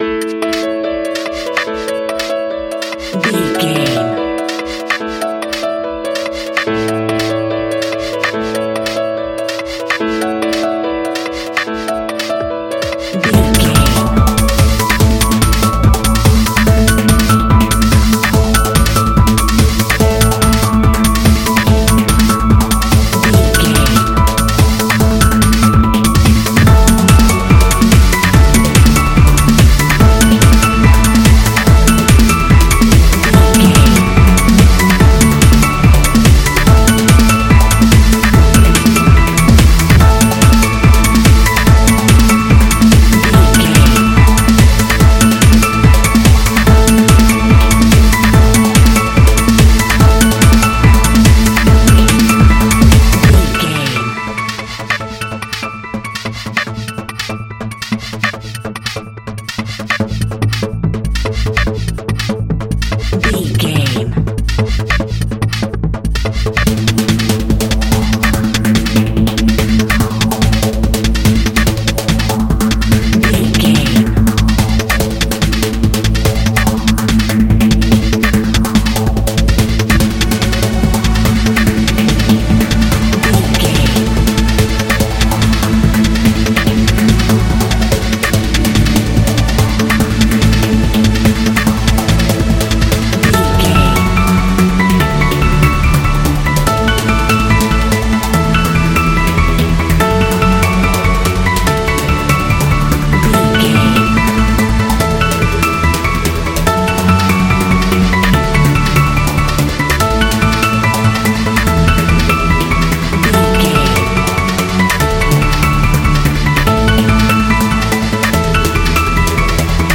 Ionian/Major
Fast
energetic
uplifting
hypnotic
drum machine
piano
synthesiser
acid house
electronic
uptempo
synth leads
synth bass